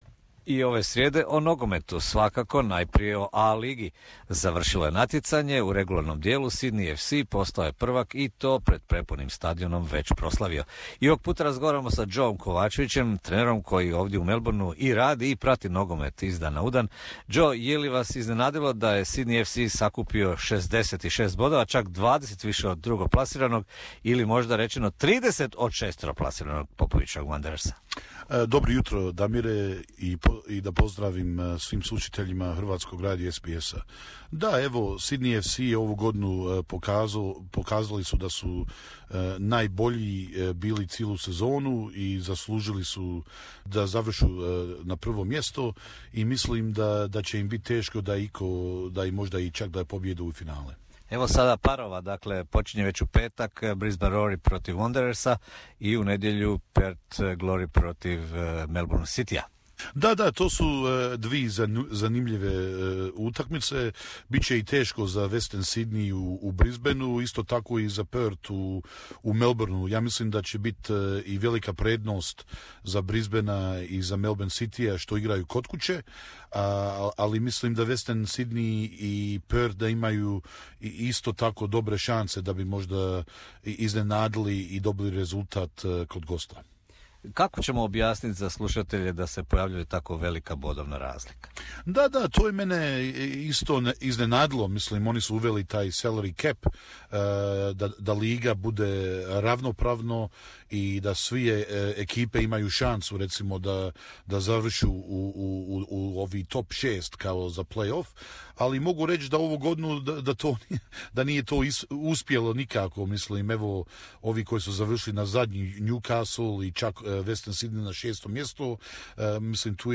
u razgovoru s trenerom